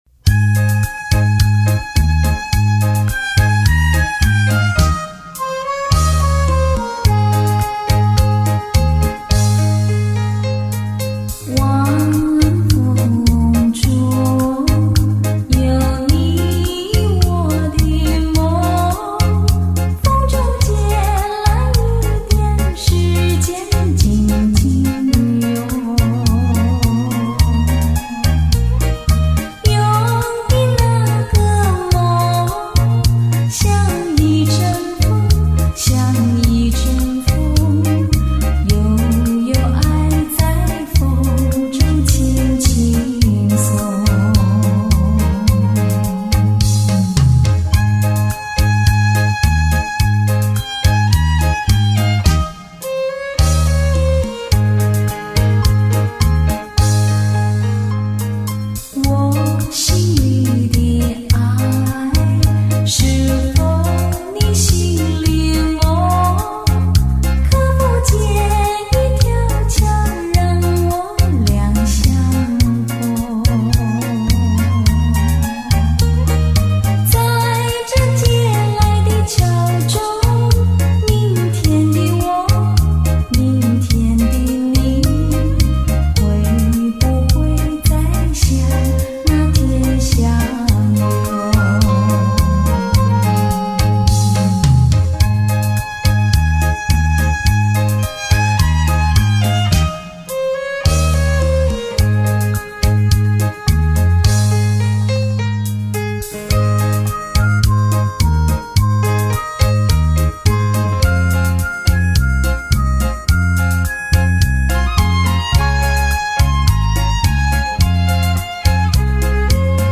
柔情似水的旋律 激昂影漾的节奏 脍炙人口的情歌 娓娓动人的舞曲....